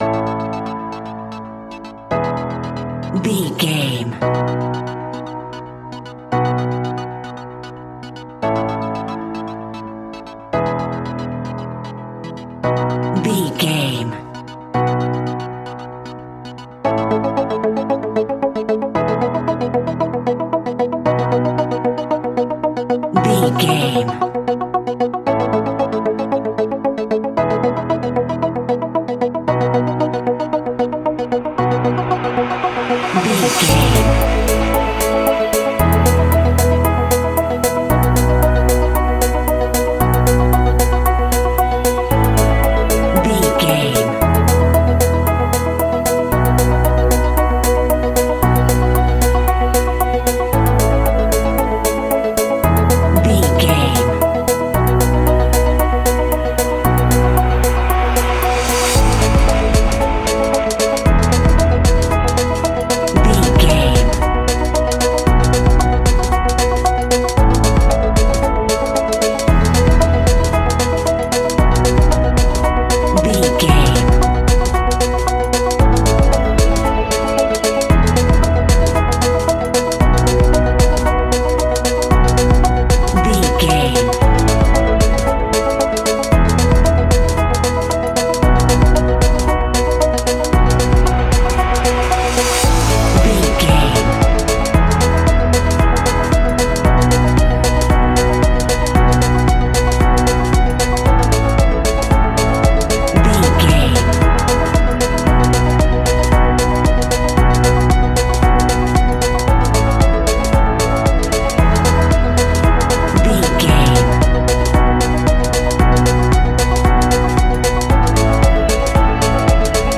Ionian/Major
energetic
uplifting
catchy
upbeat
acoustic guitar
electric guitar
drums
piano
organ
bass guitar